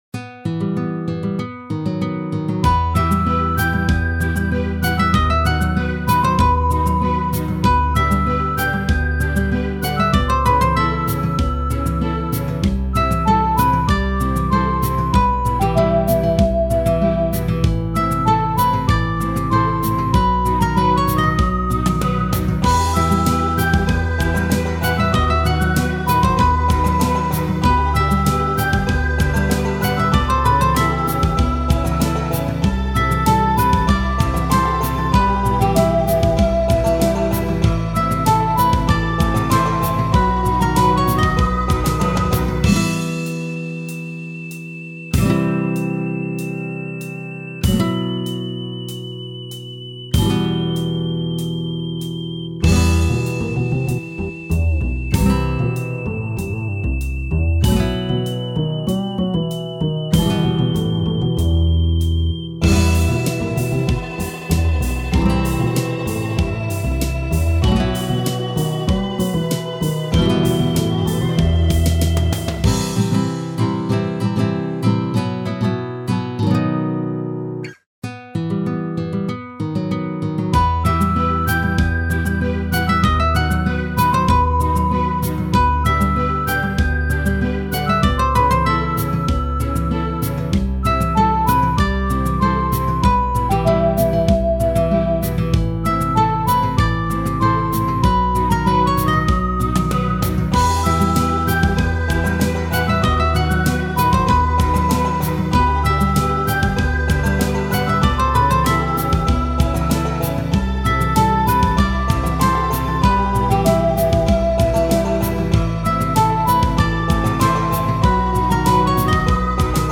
渋い